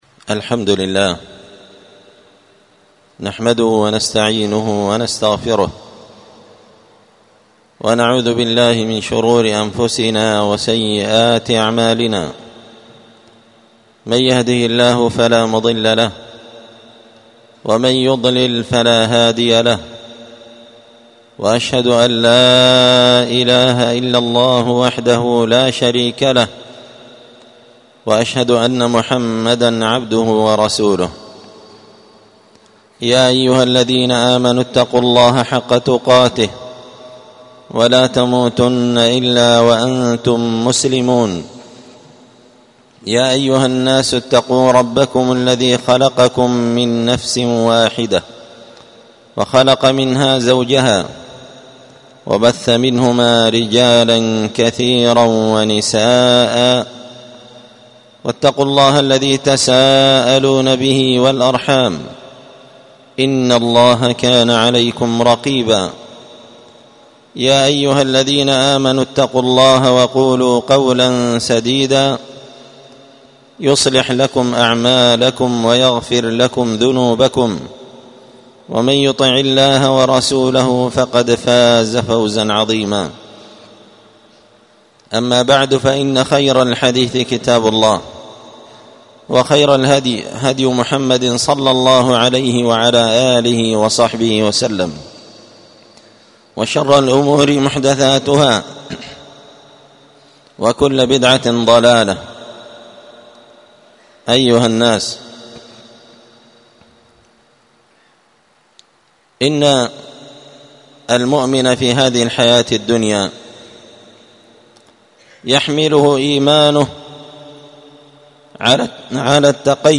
خطبة جمعة
تنبيه هذه الخطبة ألقيت مرة أخرى في مسجد آخر بسبب الوشوشة وضعف صوت في الأولى ألقيت هذه الخطبة بدار الحديث السلفية بمسجد الفرقان قشن -المهرة-اليمن تحميل